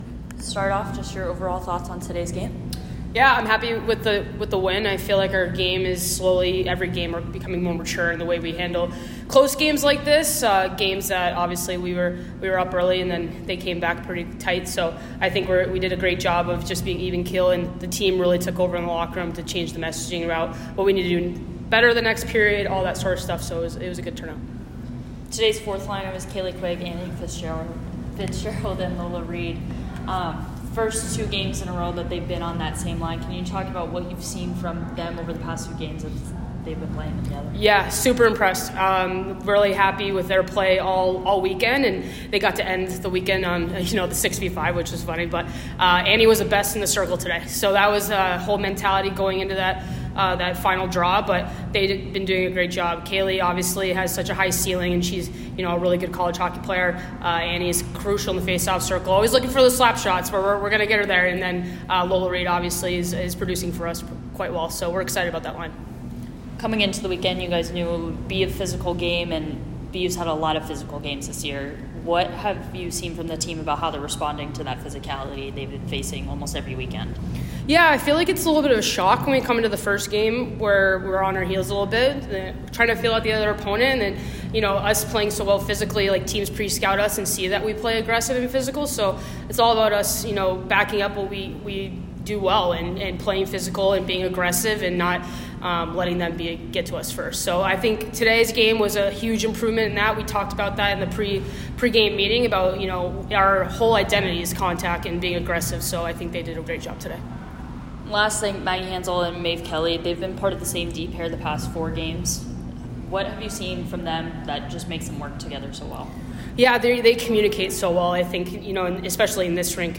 Syracuse Postgame Interview